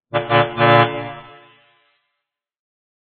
トラックのクラクション。